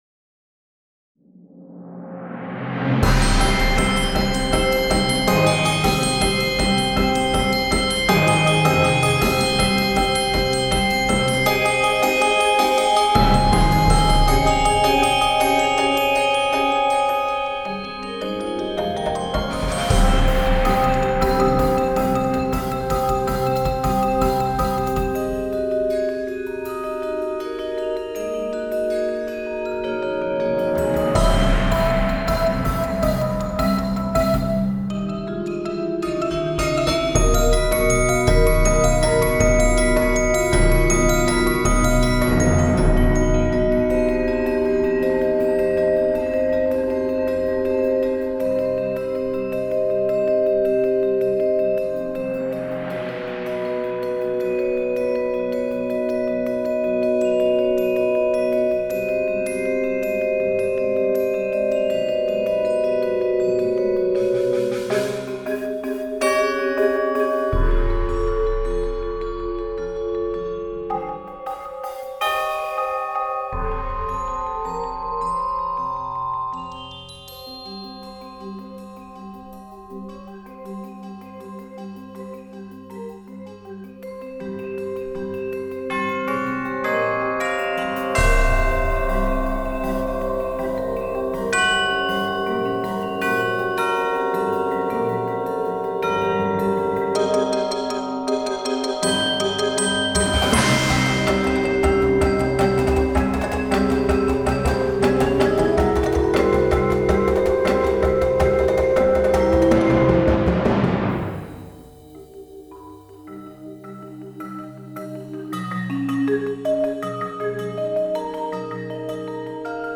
Genre: Percussion Ensemble
# of Players: 12
Player 1: Glockenspiel
Player 3: Vibraphone 1
Player 9: Timpani [4 drums], China Cymbal